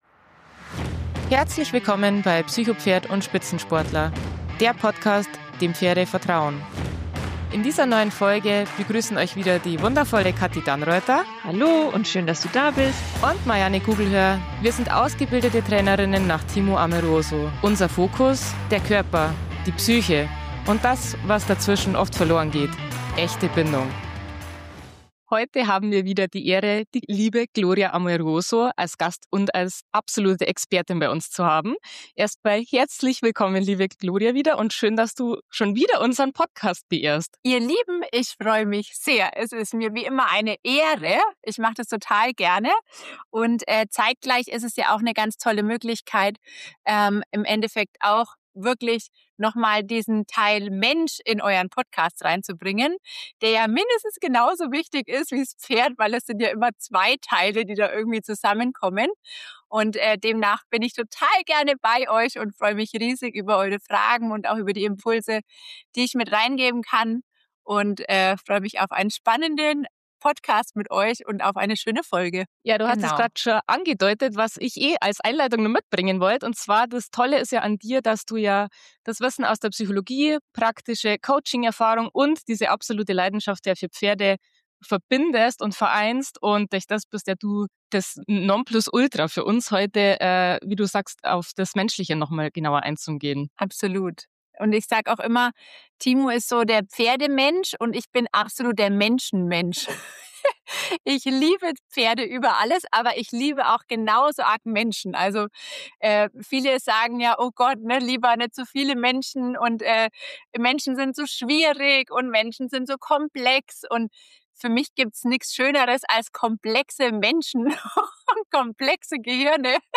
Freut euch auf ein ehrliches, tiefgehendes und inspirierendes Gespräch über Wachstum, innere Entwicklung und die besondere Rolle, die Pferde dabei spielen.